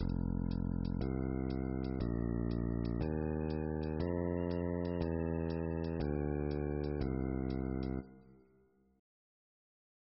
ベース
2beat